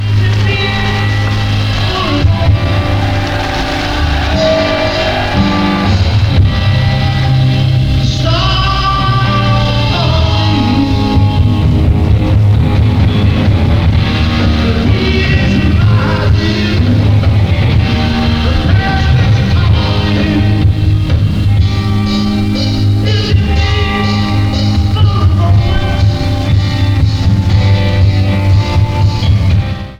Format/Rating/Source: CD - D- - Audience
Comments: Poor sound quality.
Sound Samples (Compression Added):